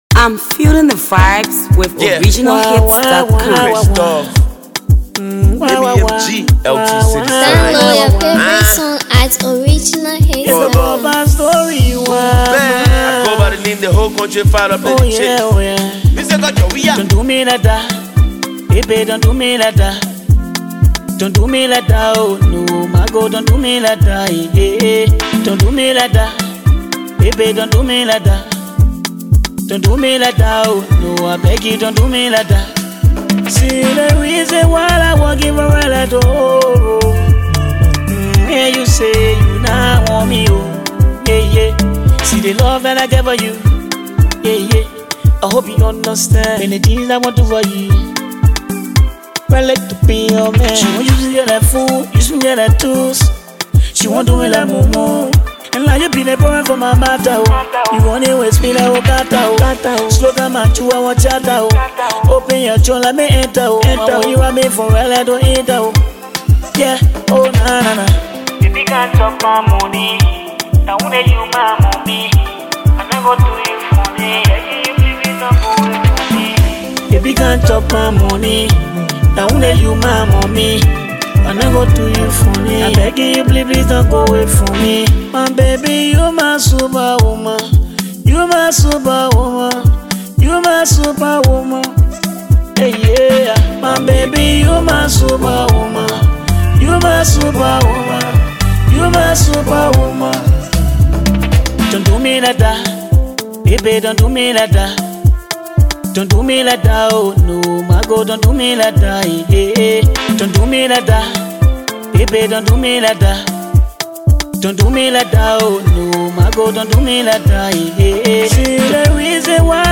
features sensational Hipco star